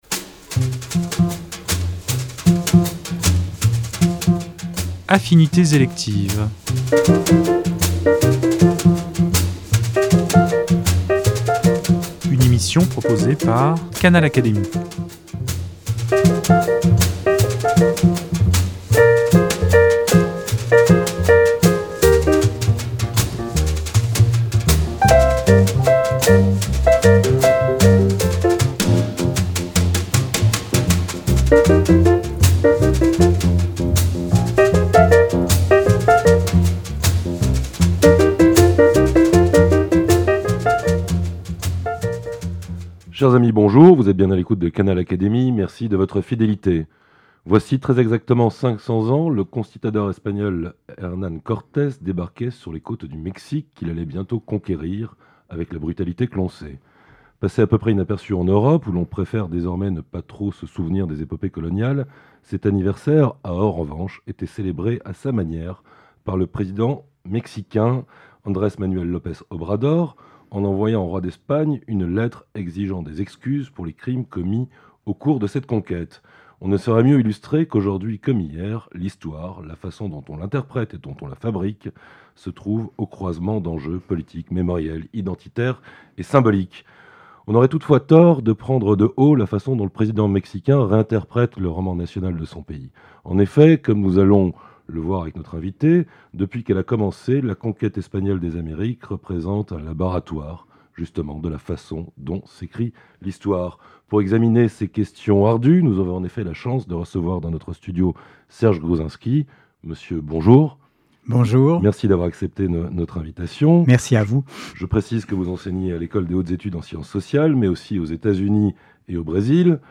Dans cet ouvrage, comme dans l’entretien qu’il nous a accordé, il s’appuie sur la colonisation du Mexique par Hernan Cortès pour mener une réflexion plus vaste sur la façon dont l’Europe, entrant dans la modernité, s’est mise à écrire l’histoire du monde. Comme il l’explique, ces événements éclairent singulièrement le défi que représente, pour notre continent, le sentiment de n’être plus le centre de l’histoire mondiale.